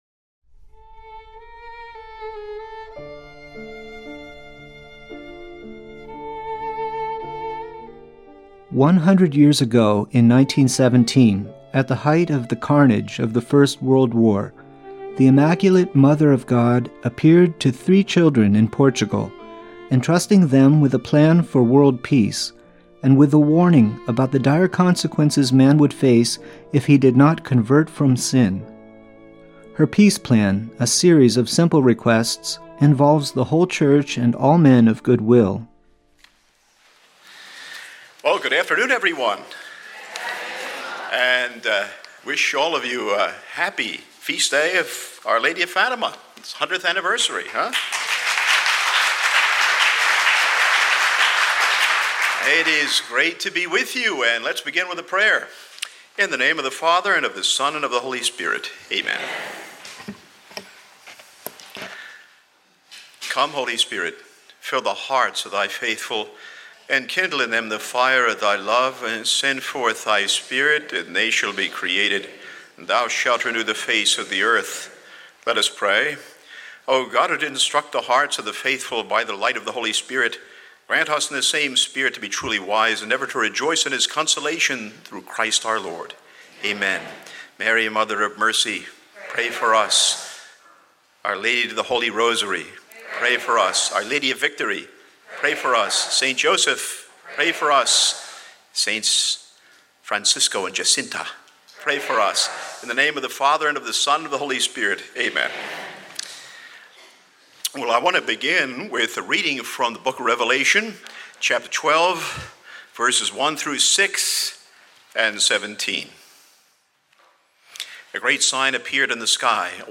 May 13 Live Stream Talk